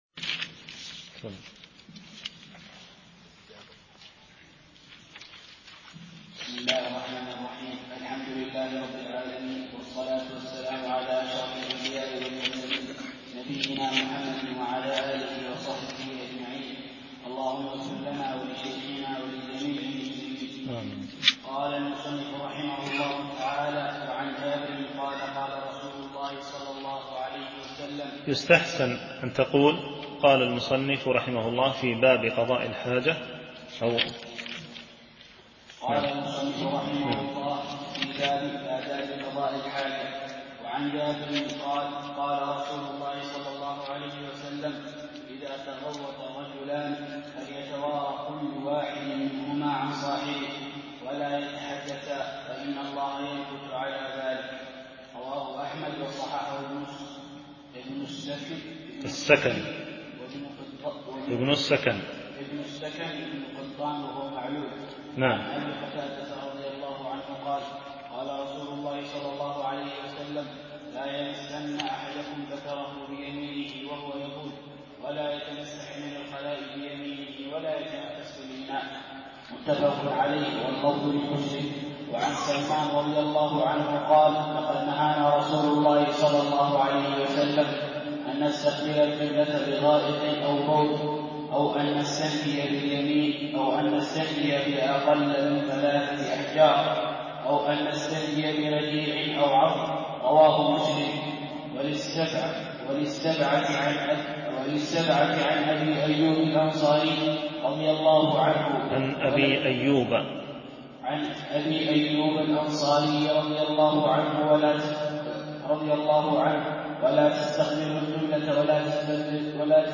شرح كتاب بلوغ المرام من أدلة الأحكام - الدرس 12 ( كتاب الطهارة، الحديث 88-95)